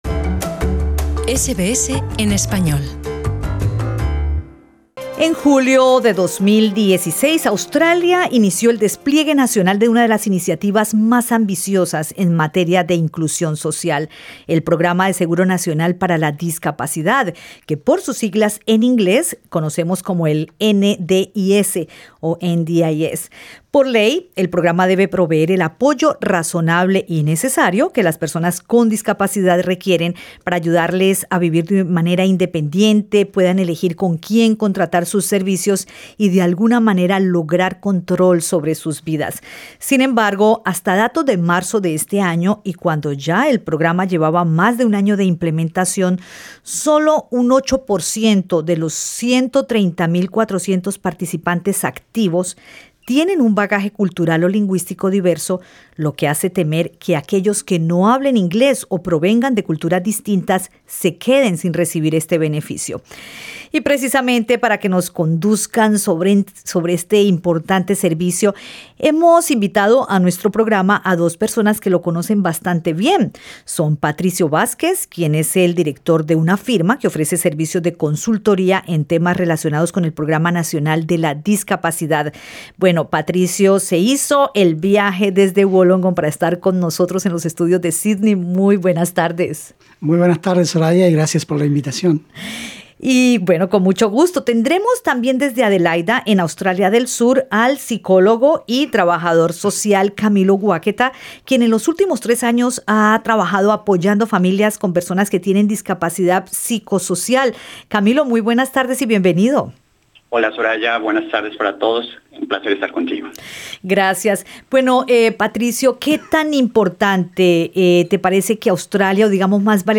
Escucha el podcast con la entrevista, en la que ellos explican cómo funciona el NDIS, los pasos que hay que seguir para acceder a los servicios y quiénes son elegibles, entre otros aspectos.